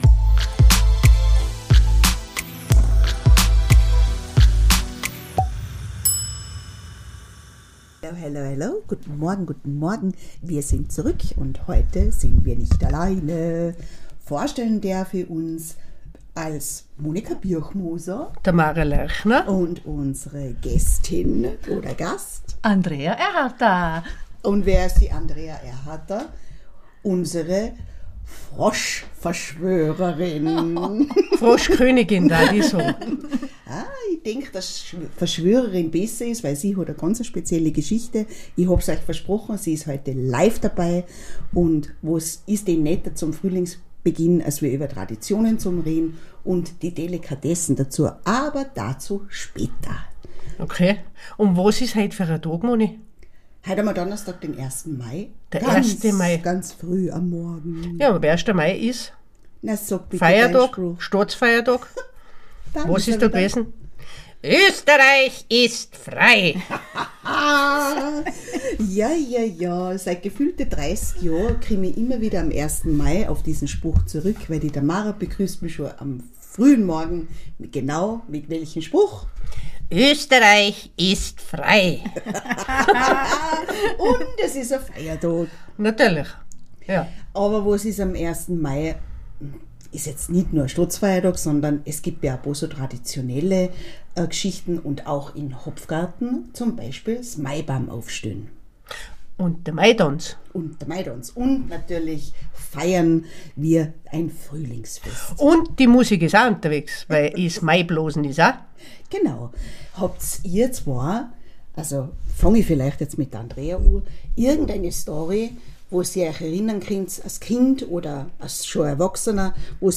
eine illustre Runde die sich unterhält über dies und das. Auch über Essen und Trinken. Maibaum aufstellen, Mai Tradtionen, WEINHERBST im zeitlos, 1. Mai was ist da eigentlich gewesen?